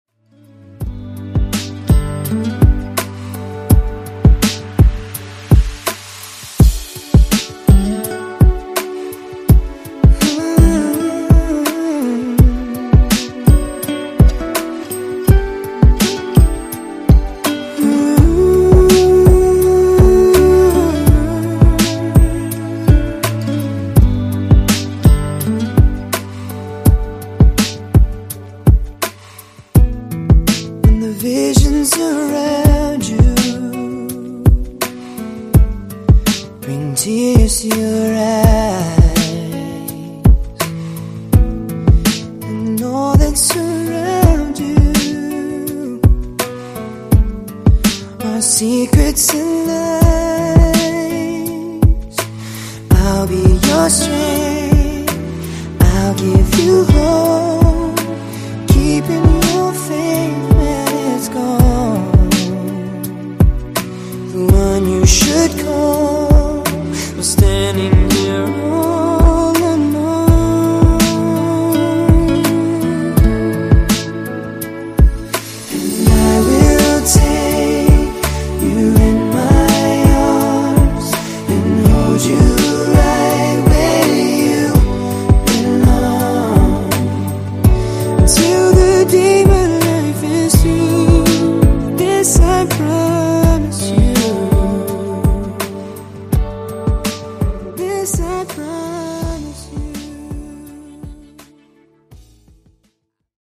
Genre: RE-DRUM Version: Clean BPM: 82 Time